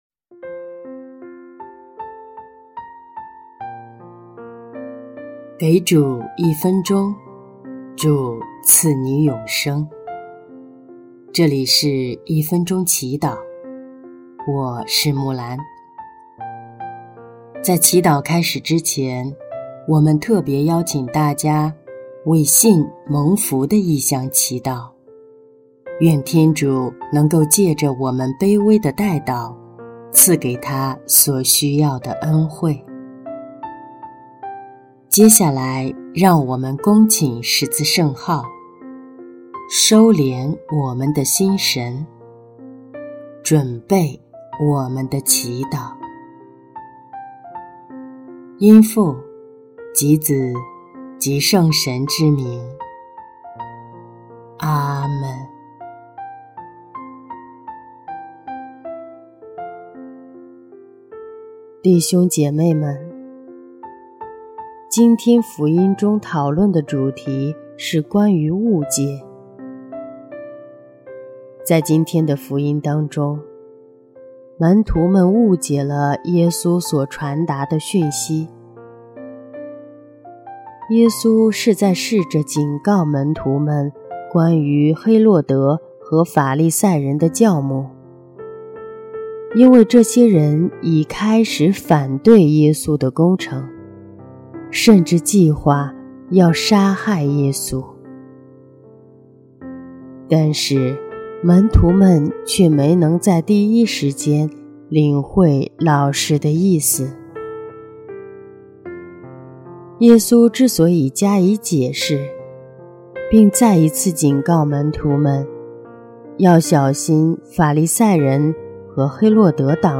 首页 / 祈祷/ 一分钟祈祷